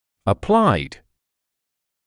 [ə’plaɪd] [э’плайд] приложенный (часто о силе); прикладной (applied knowledge прикладные знания); нанесённый